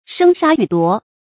注音：ㄕㄥ ㄕㄚ ㄧㄩˇ ㄉㄨㄛˊ
生殺予奪的讀法